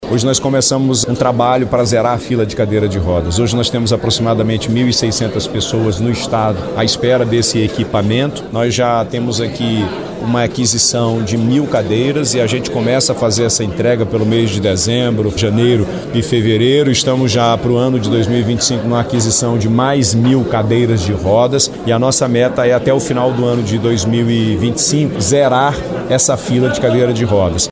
O evento foi realizado no Centro de Convenções Vasco Vasques, em Manaus e comemorou o Dia Nacional da Acessibilidade e o Dia Internacional da Pessoa com Deficiência.
SONORA-1-GOVERNADOR-WILSON-LIMA-.mp3